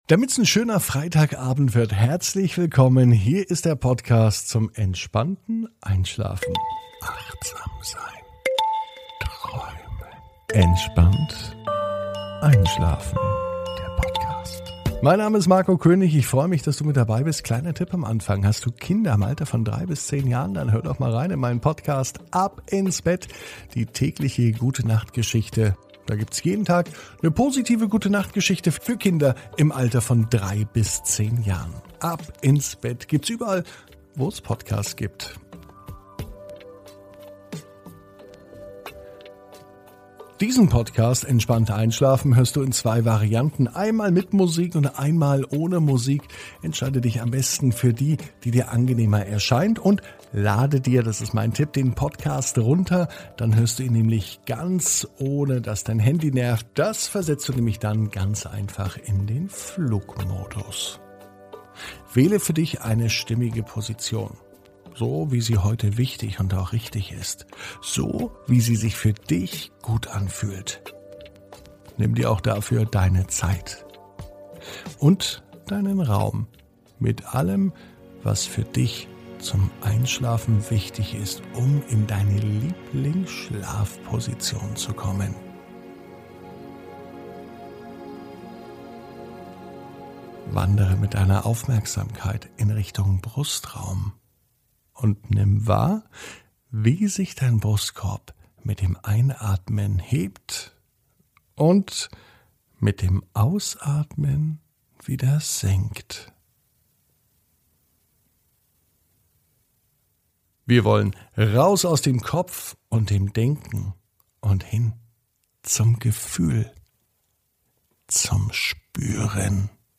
(ohne Musik) Entspannt einschlafen am Freitag, 28.05.21 ~ Entspannt einschlafen - Meditation & Achtsamkeit für die Nacht Podcast